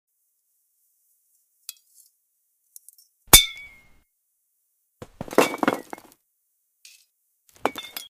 ASMR glass garden vegetables, Pepper